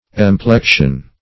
Emplection \Em*plec"tion\, n.